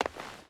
Footsteps / Stone
Stone Run 2.wav